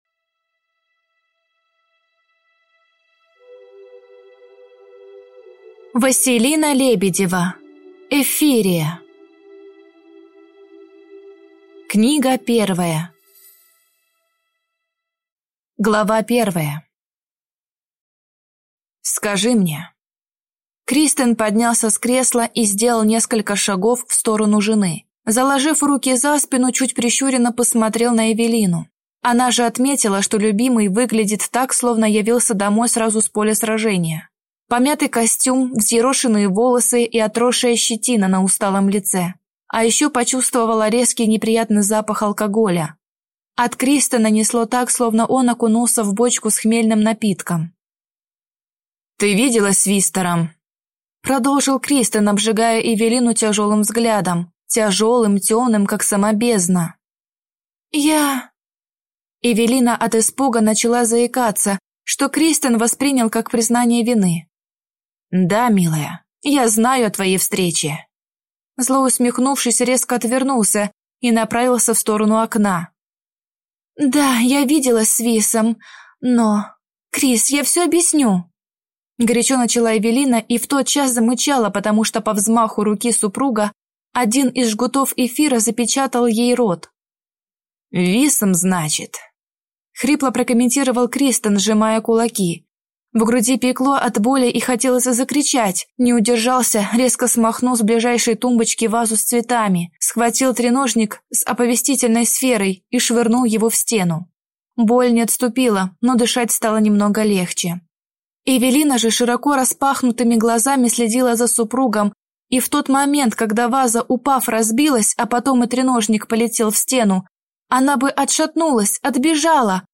Аудиокнига Эфиррия | Библиотека аудиокниг
Прослушать и бесплатно скачать фрагмент аудиокниги